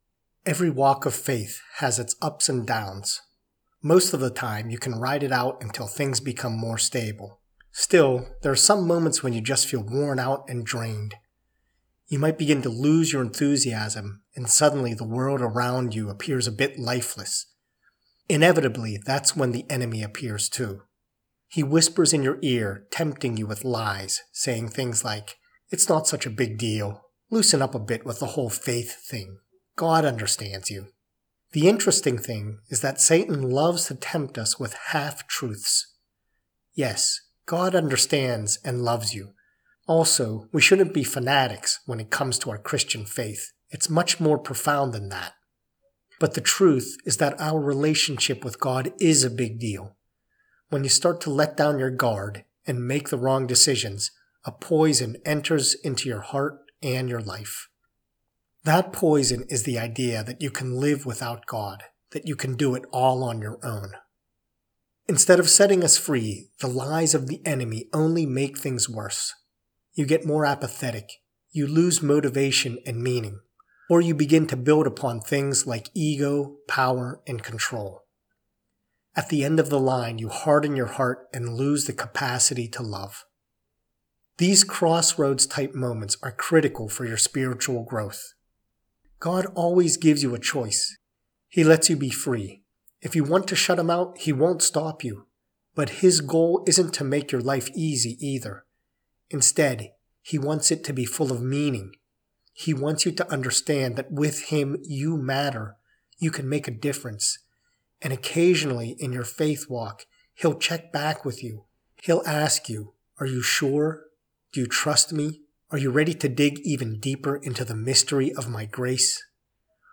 PRAYER WHEN YOU NEED TO BOUNCE BACK